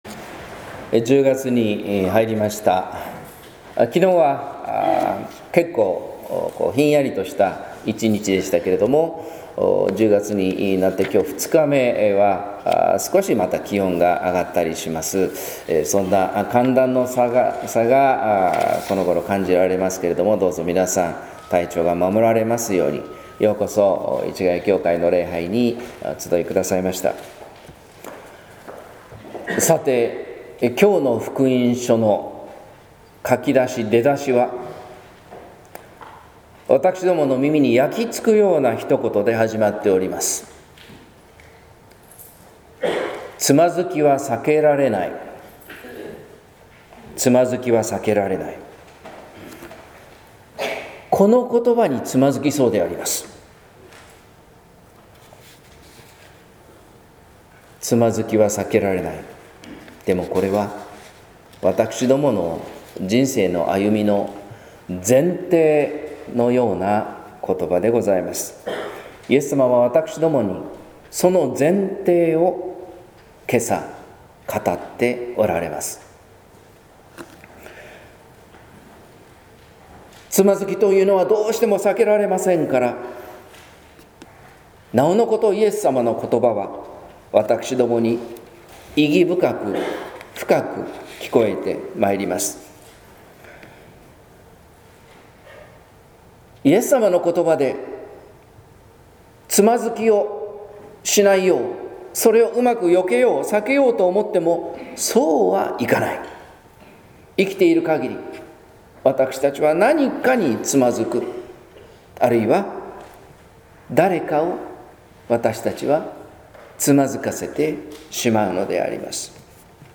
説教「芥子粒の信仰が生きる」（音声版）